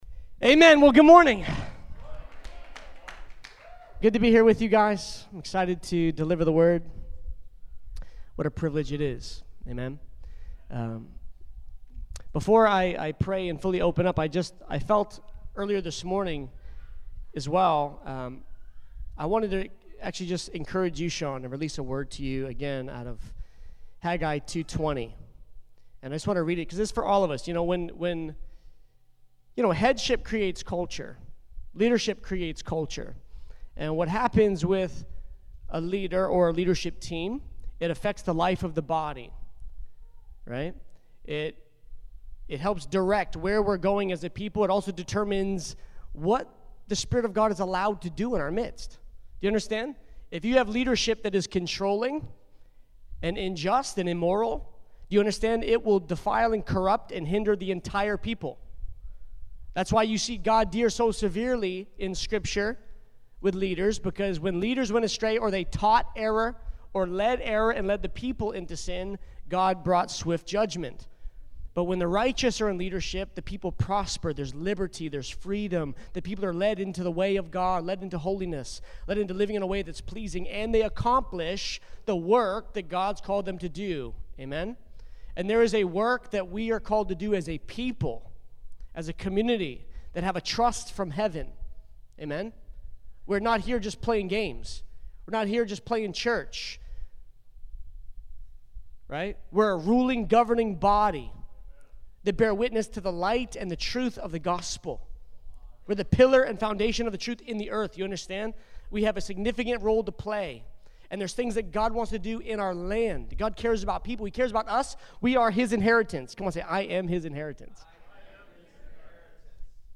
6-20-21-service-mono.mp3